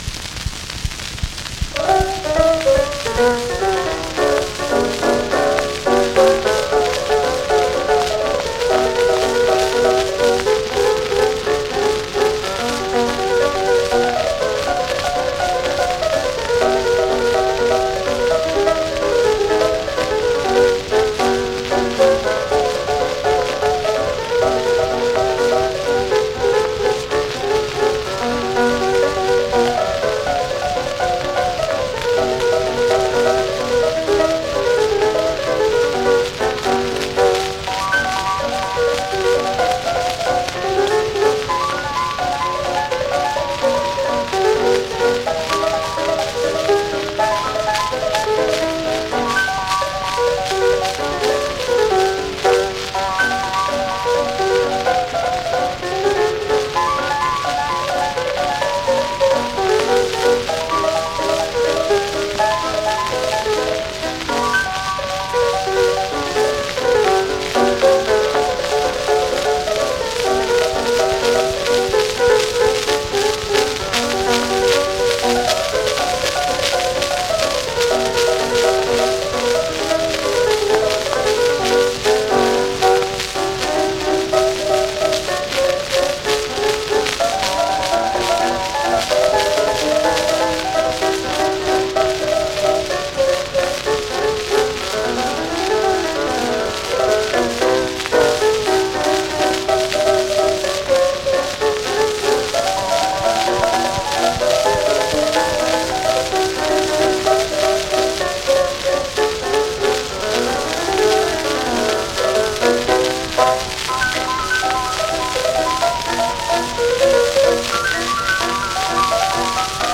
Ragtime music.